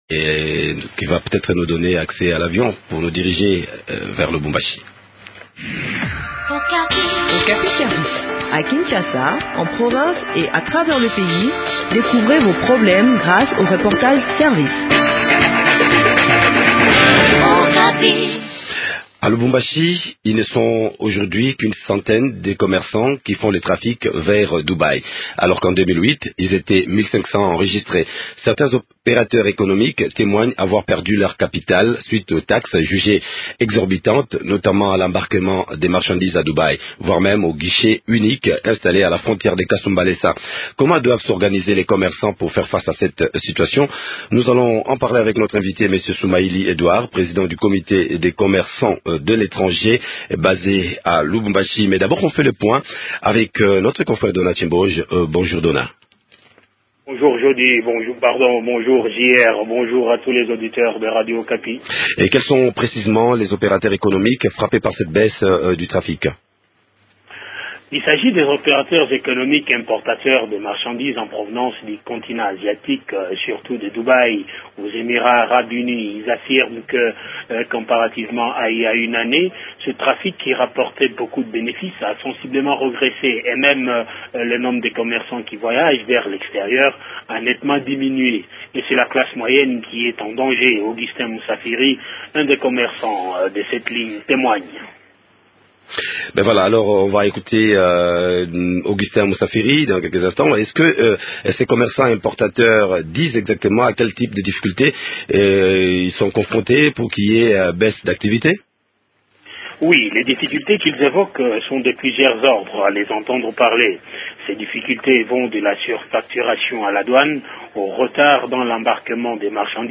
Le point de la situation dans cet entretient